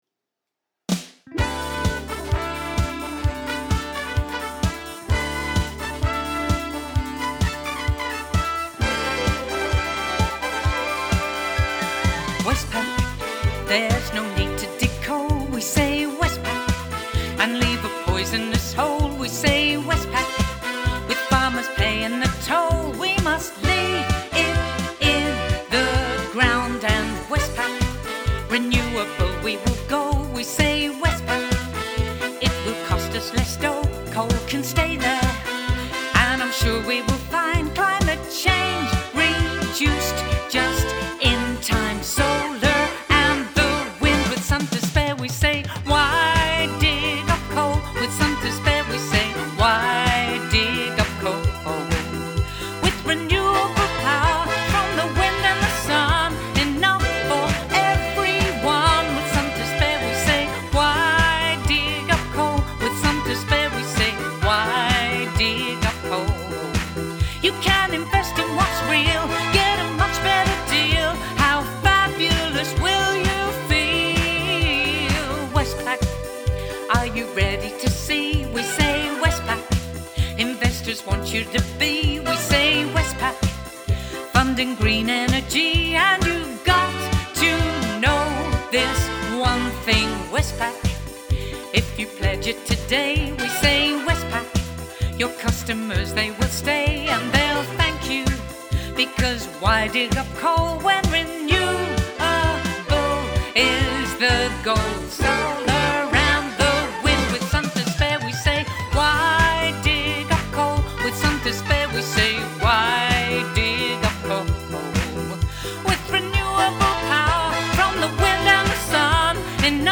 Song with vocal https